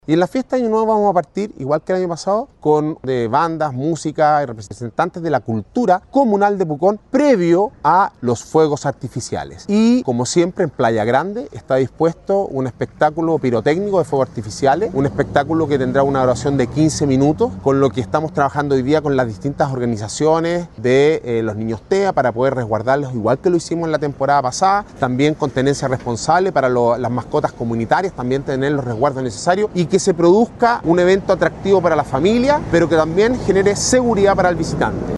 Alcalde-Sebastian-Alvarez-confirma-fuegos-artificiales-ademas-de-cuidados-a-personas-y-animales-.mp3